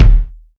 KICK.62.NEPT.wav